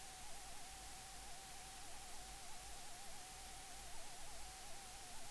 Propeller Geräusche bei Input
Nun habe ich auch mein neues Mikro, das t.bone SCT 800 an das Mischpult angeschlossen.